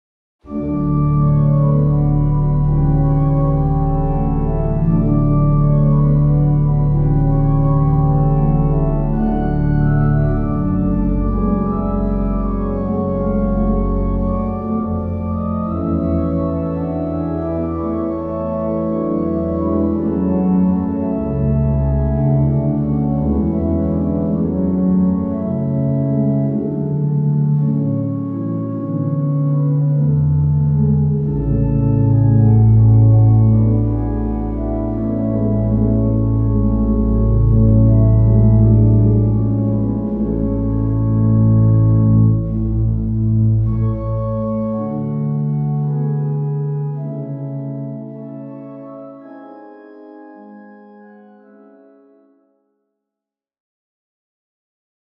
●リードオルガン 楽譜(手鍵盤のみで演奏可能な楽譜)